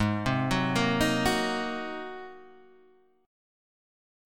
G# 9th